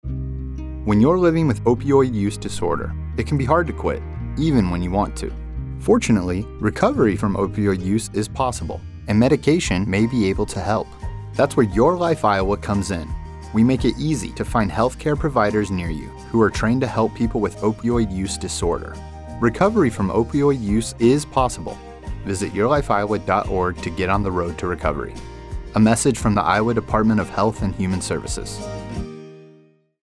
This programmatic 30 second radio spot is licensed indefinitely.